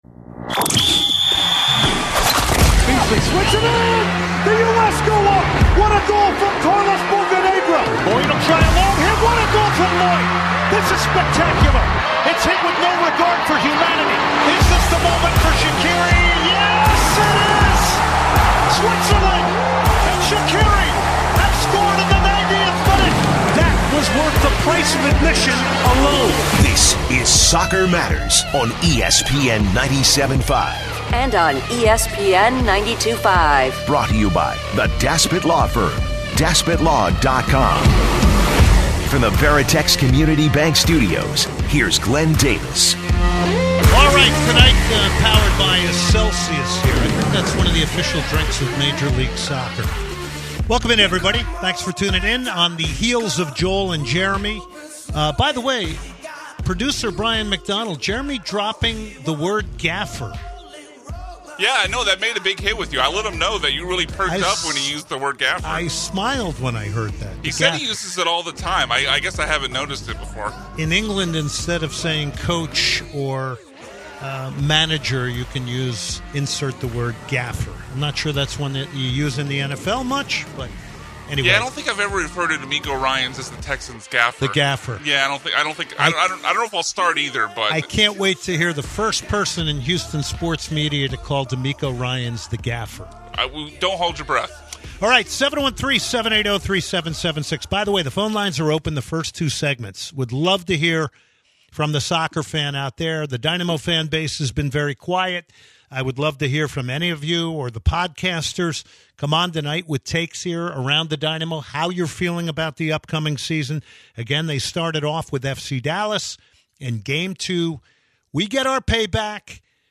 PLUS, a great conversation with Dynamo coach Ben Olsen